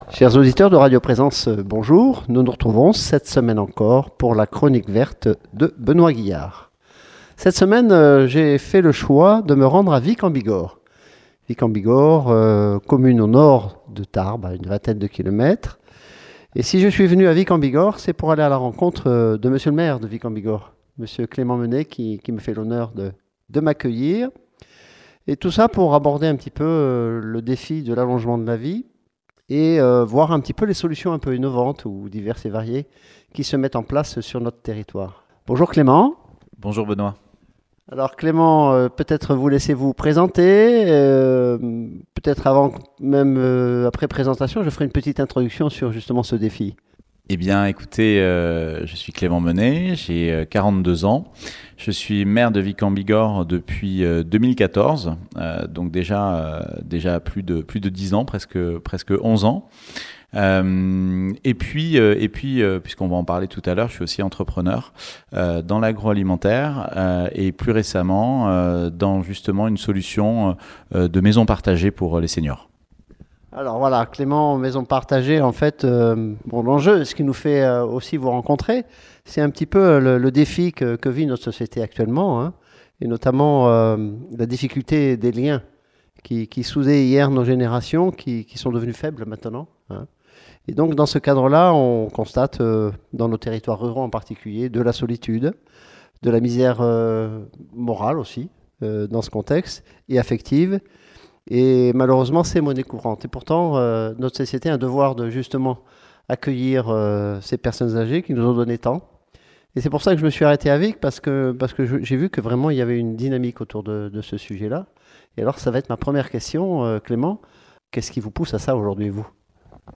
Dans le cadre de la chronique verte sur le sujet du défi de l’allongement de la vie et les réponses proposées par une collectivité dynamique sur ce sujet qu’est Vic en Bigorre. Il s’agit en particulier de l’interview de son maire, Clément Menet et de la présentation de différentes approches concernant les hébergements et les services proposés à nos aînés sur cette commune. Au delà des EHPAD, se mettent en place progressivement d’autres formules telles que les résidences inclusives et les collocations séniors...